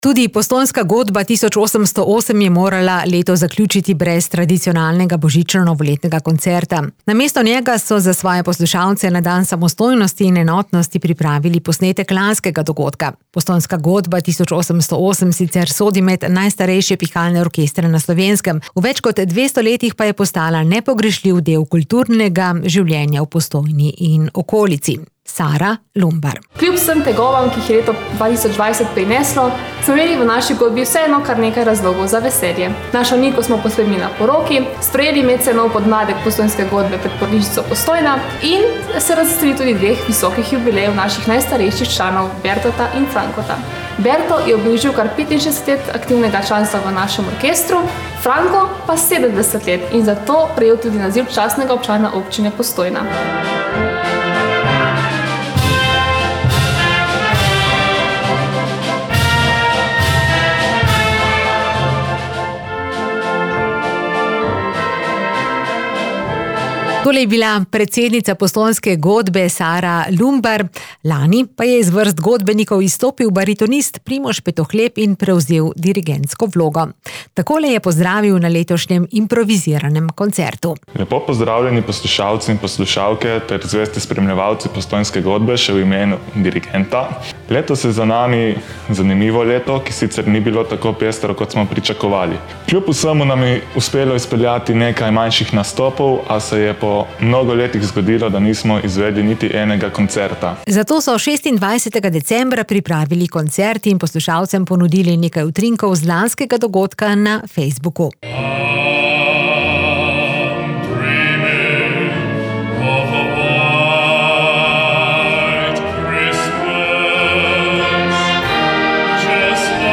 Postojnska godba 1808 sodi med najstarejše pihalne orkestre na Slovenskem.